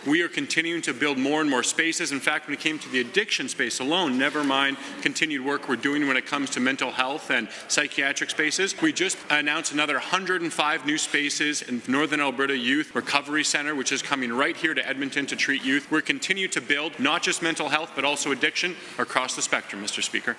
During a debate in the legislature this week, Williams brought up some of the work the government has been doing on expanding mental health resources.